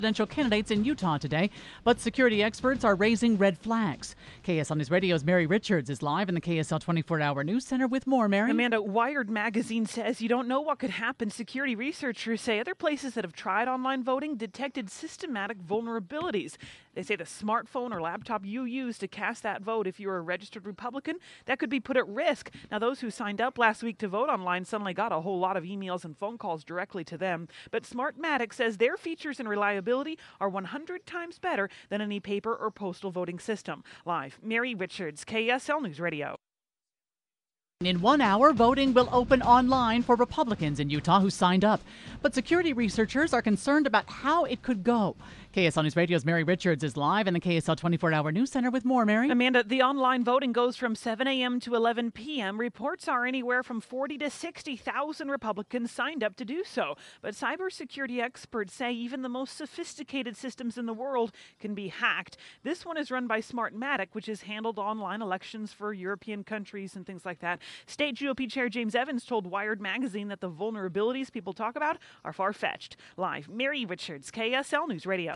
The Utah Republican Party paid to use the online system for the presidential preference vote; cyber security researchers talk about their concerns.